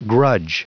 Prononciation du mot grudge en anglais (fichier audio)
Prononciation du mot : grudge